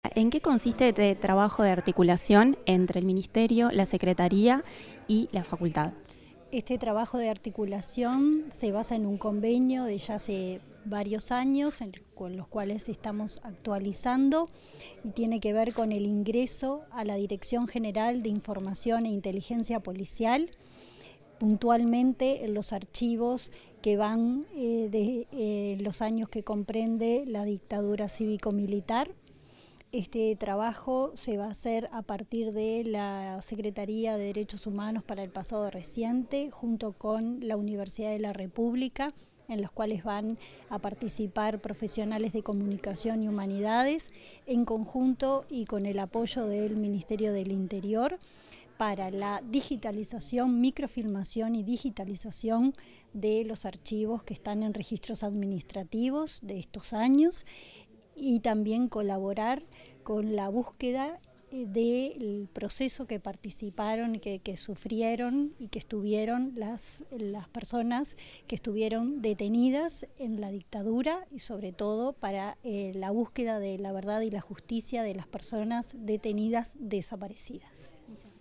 Declaraciones de la subsecretaria del Ministerio del Interior, Gabriela Valverde